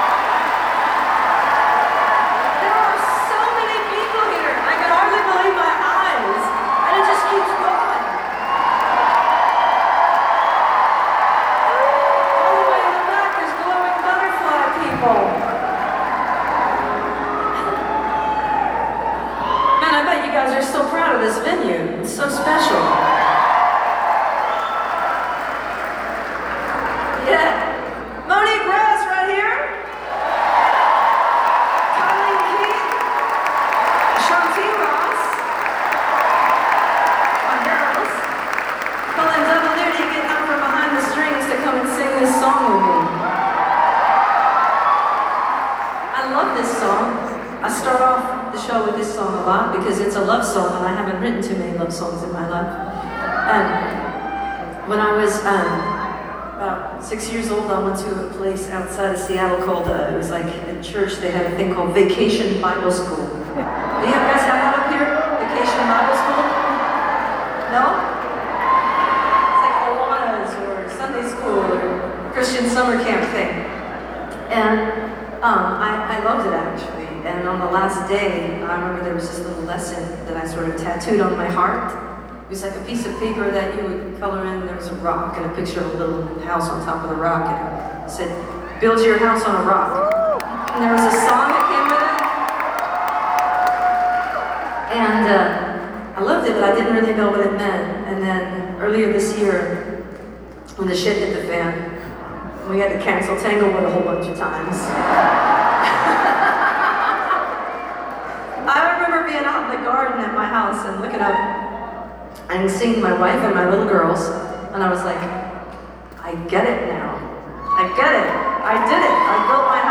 (audio capture from a facebook live stream)